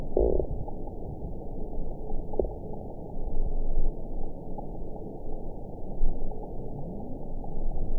event 921570 date 12/04/24 time 01:36:51 GMT (6 months, 2 weeks ago) score 6.44 location TSS-AB07 detected by nrw target species NRW annotations +NRW Spectrogram: Frequency (kHz) vs. Time (s) audio not available .wav